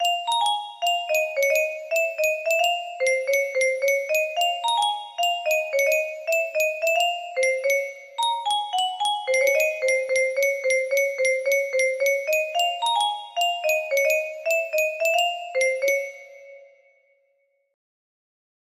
The music box melody